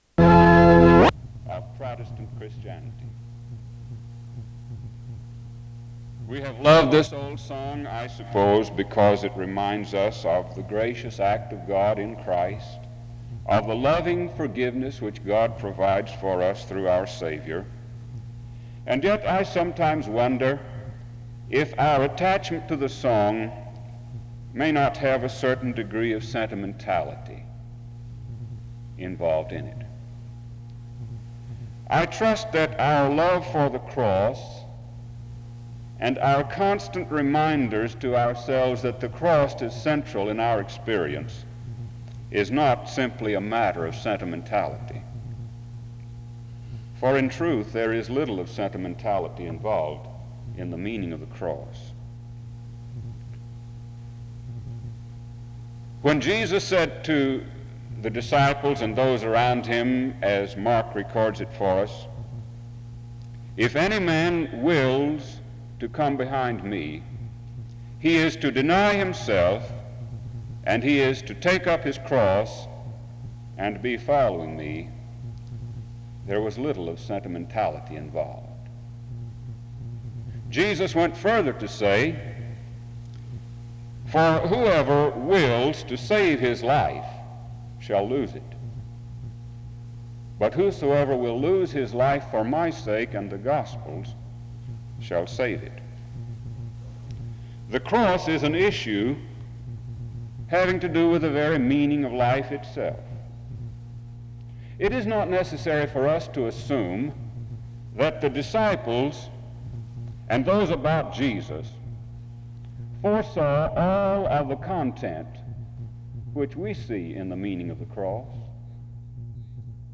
The service then concludes with a hymn (15:45-end).
SEBTS Chapel and Special Event Recordings SEBTS Chapel and Special Event Recordings